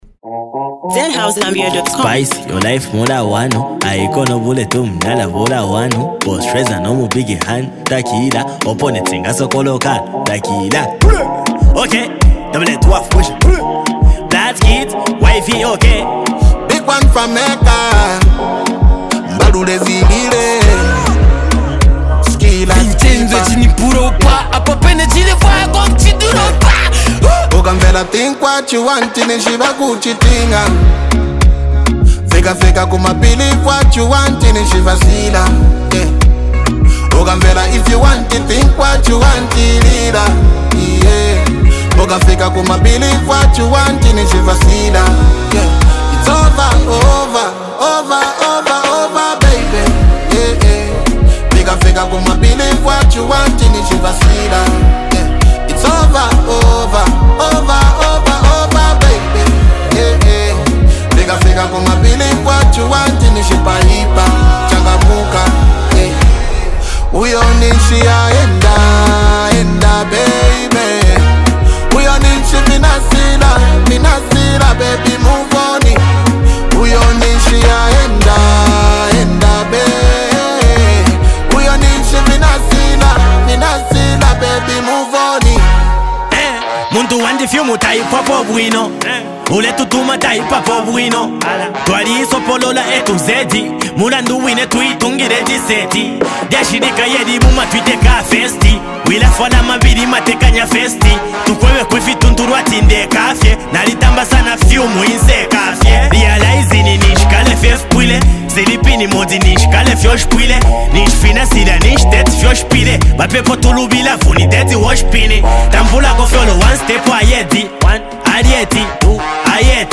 Packed with energy and real-life vibes, it’s a must-watch.